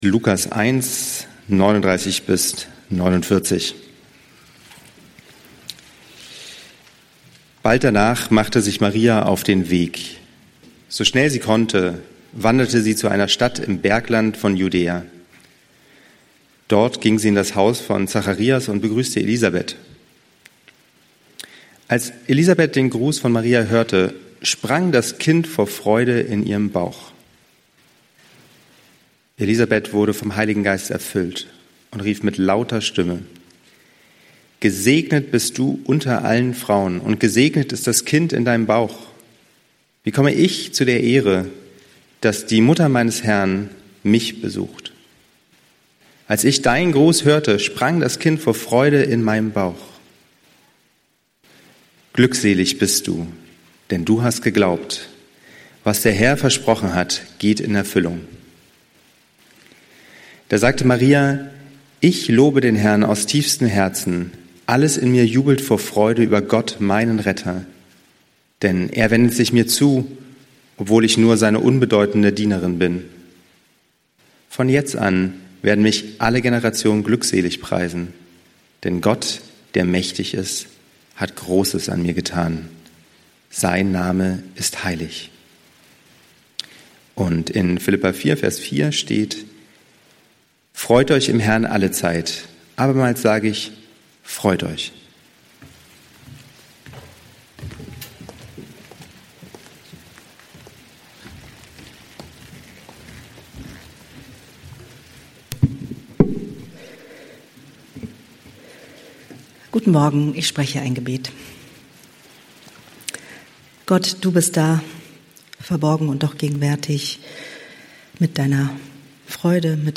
Achtsam im Advent - Freude Raum geben ~ Berlinprojekt Predigten Podcast